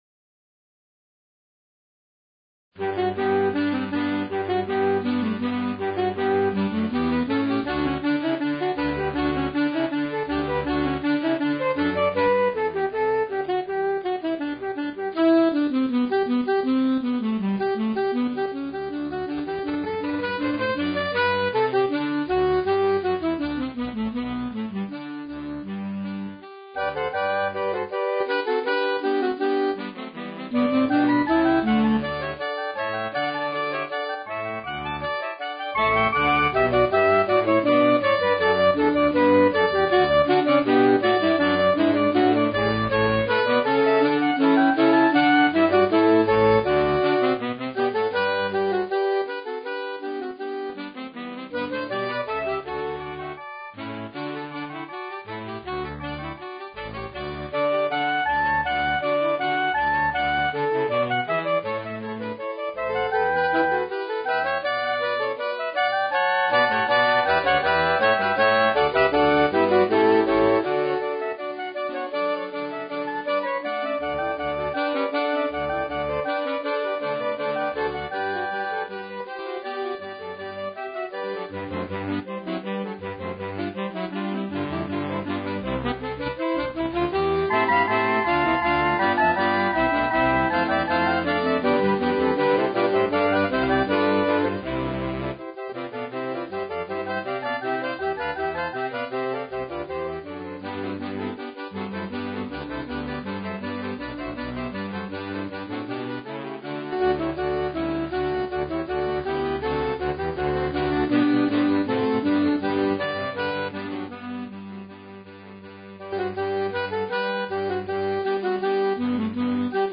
Per coro di sax